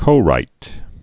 (kō-rīt) or co-write